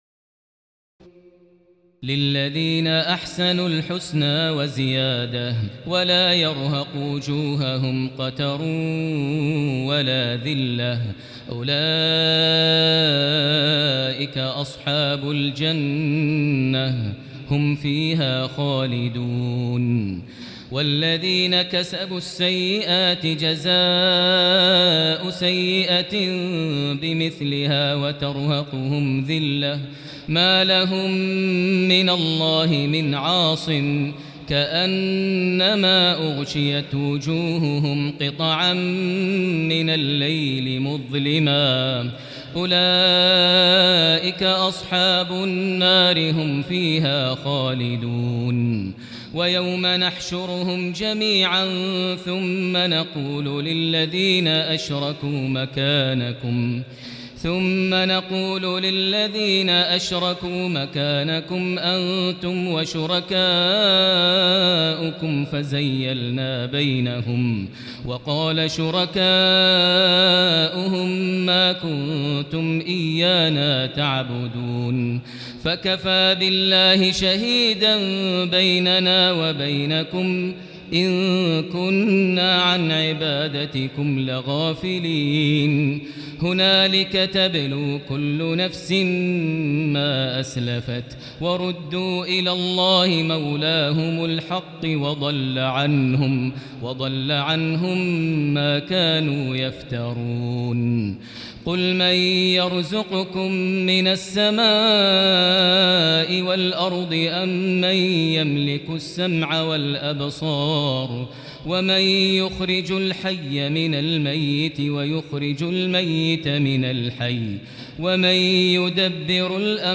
تراويح الليلة العاشرة رمضان 1439هـ من سورتي يونس (26-109) و هود (1-5) Taraweeh 10 st night Ramadan 1439H from Surah Yunus and Hud > تراويح الحرم المكي عام 1439 🕋 > التراويح - تلاوات الحرمين